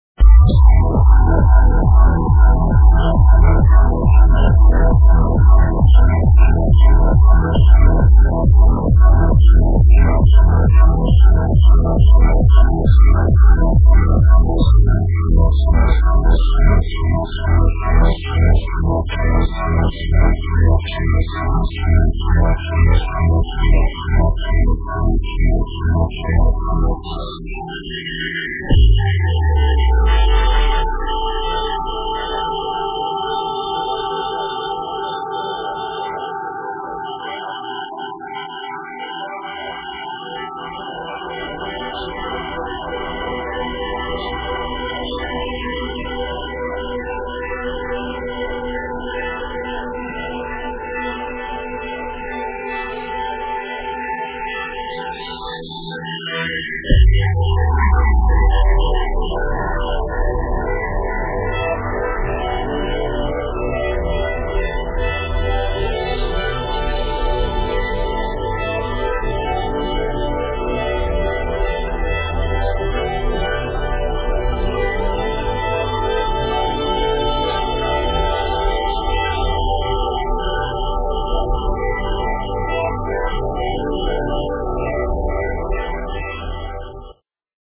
Unknown Trance Song - Please Help